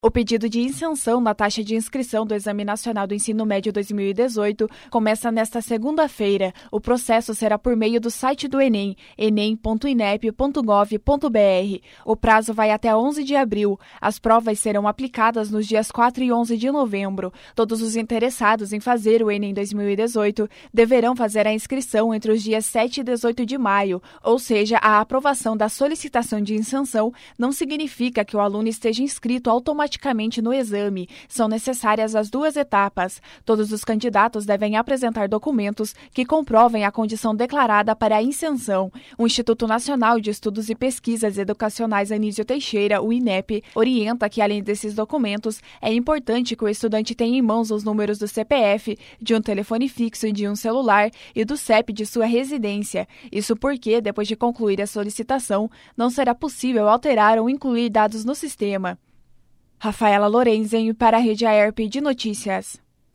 02.04 – BOLETIM/SEM TRILHA – Começa nesta segunda-feira o pedido de isenção da taxa de inscrição do Enem 2018